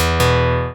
ScreenReaderOn.opus